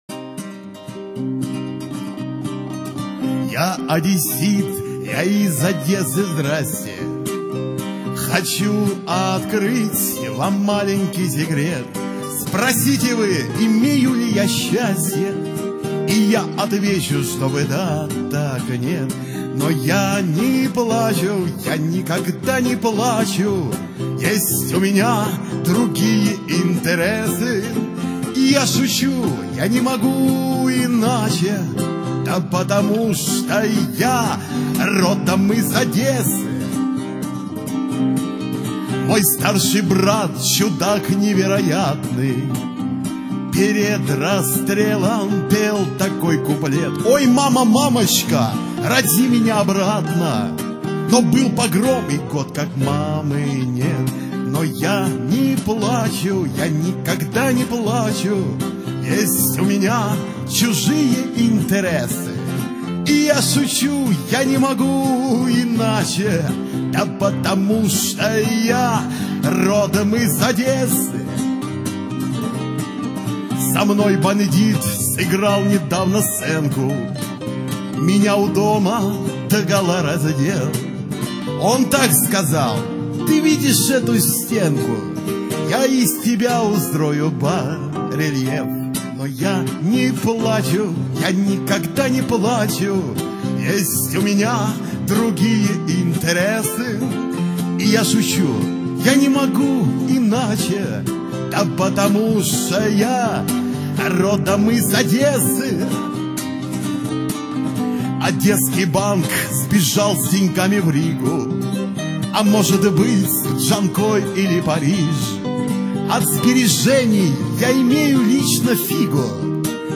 Архив ресторанной музыки